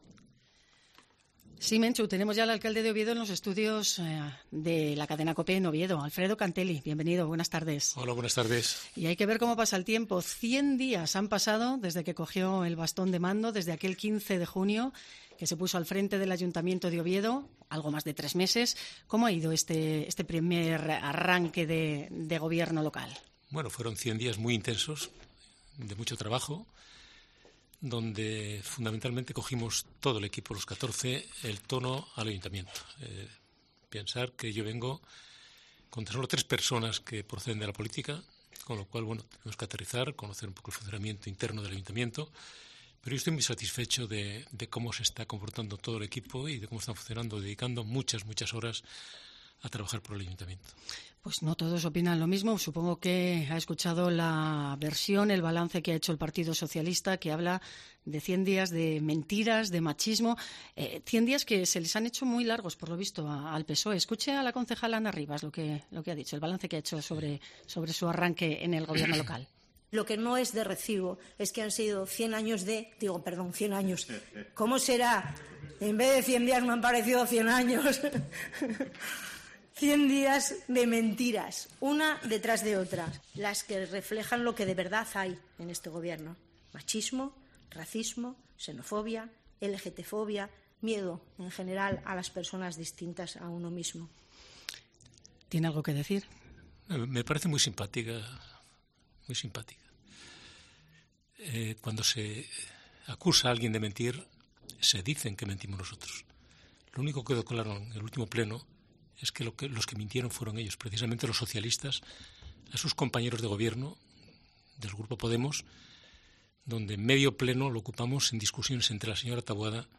Entrevista con el Alcalde de Oviedo